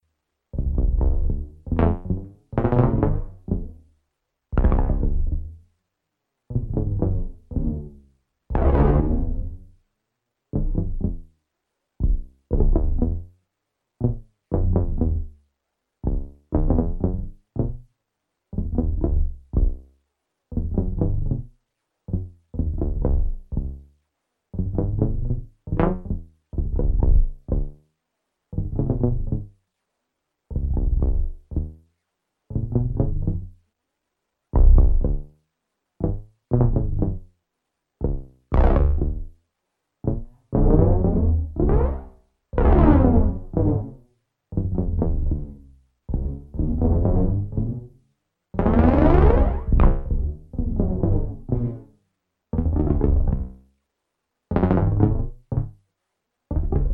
一般的なパターンとは一味も二味もズラしてくるリズム、そしてザラついた質感の妙。